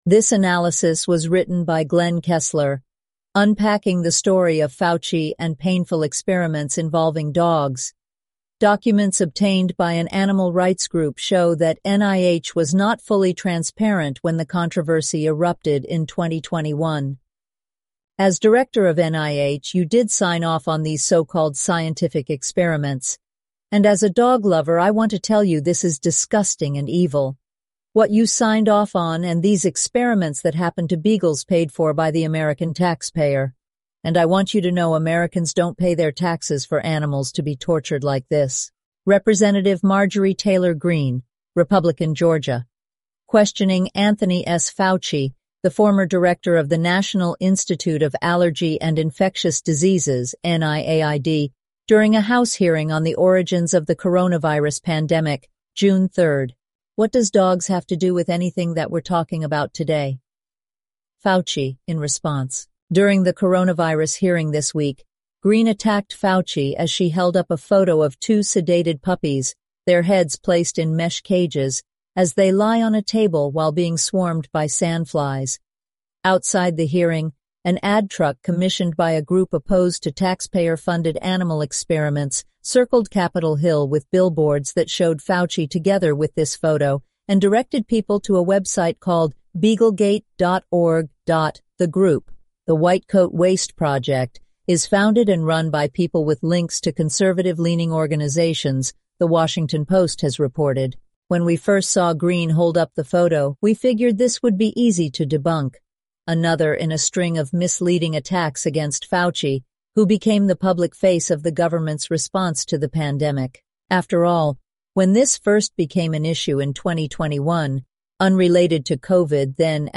eleven-labs_en-US_Maya_standard_audio.mp3